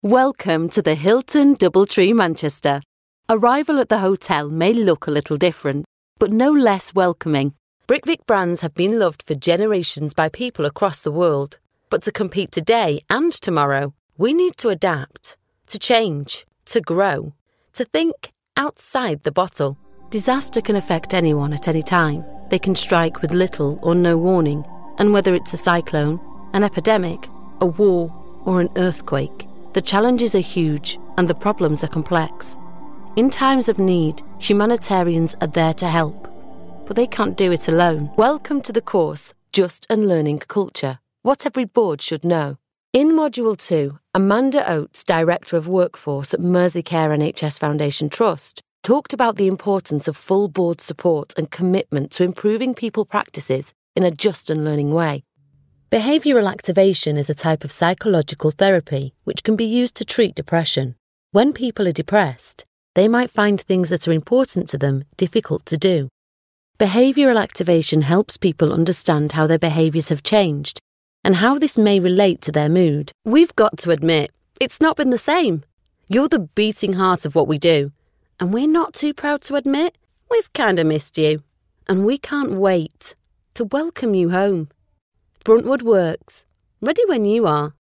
British Female Voice over with 16 years experience and a Professional studio.
Warm, engaging and conversational
Sprechprobe: Industrie (Muttersprache):